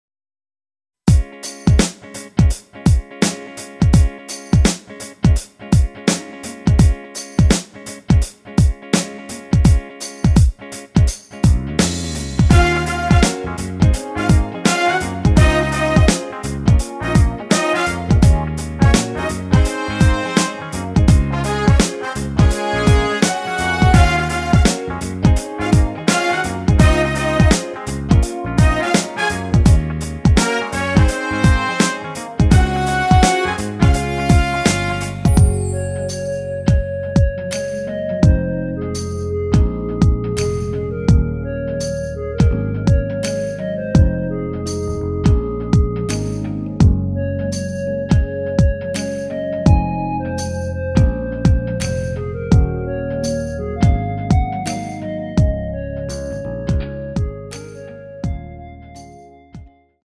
멜로디 MR입니다.
엔딩이 페이드 아웃 이라 엔딩 부분 만들어 놓았습니다.
원키 멜로디 포함된 MR입니다.
Db
앞부분30초, 뒷부분30초씩 편집해서 올려 드리고 있습니다.